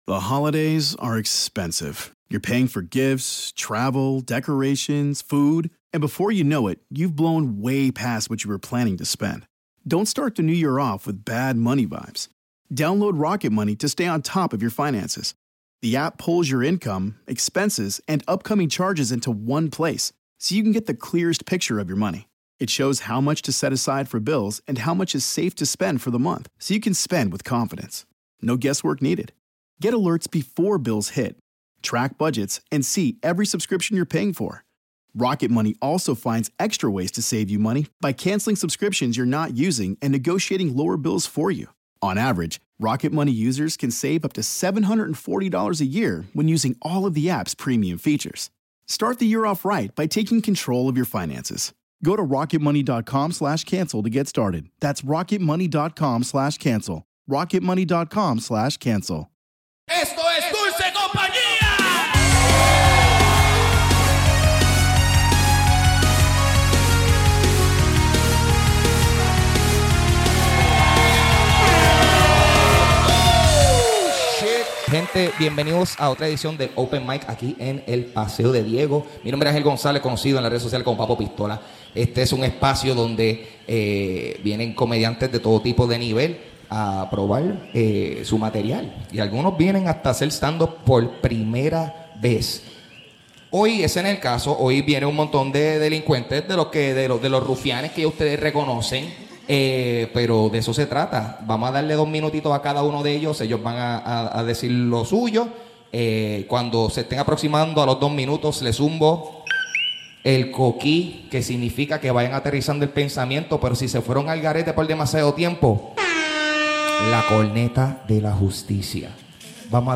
El show es brevemente interrumpido por lluvia luego de que determinamos que una boda sin barra abierta es una porquería.